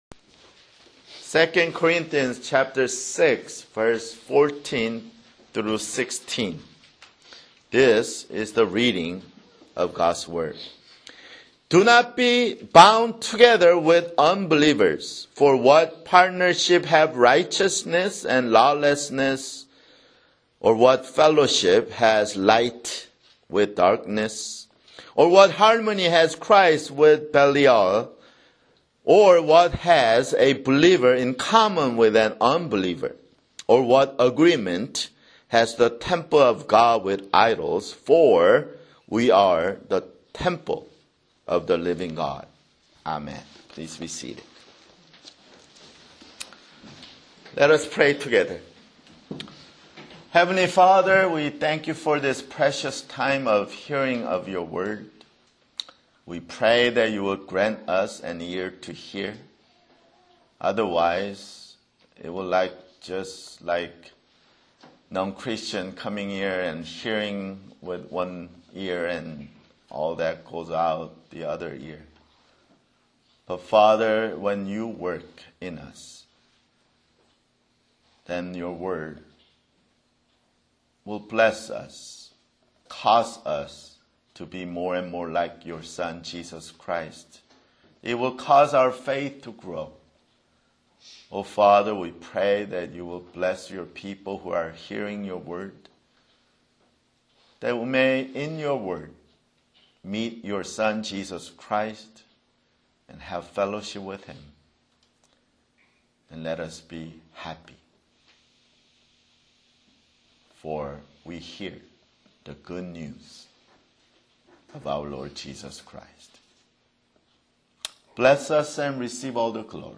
[Sermon] 2 Corinthians (39)